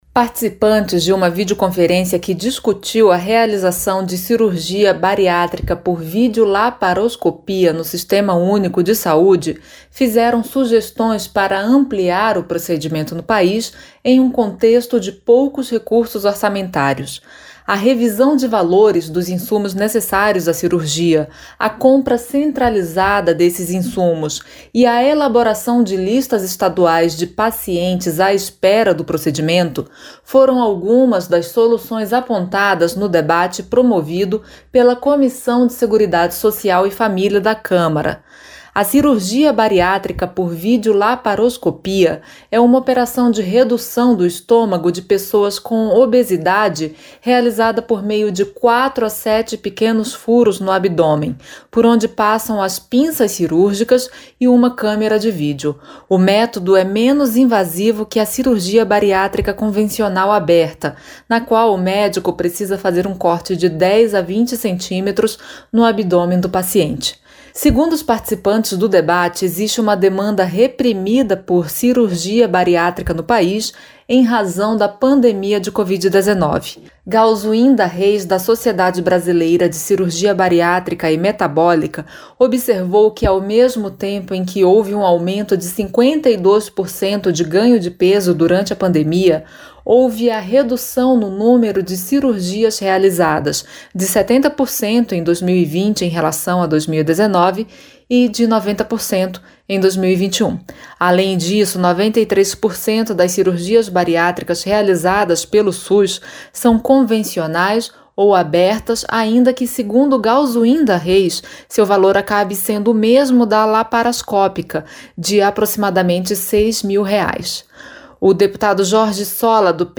• Áudio da matéria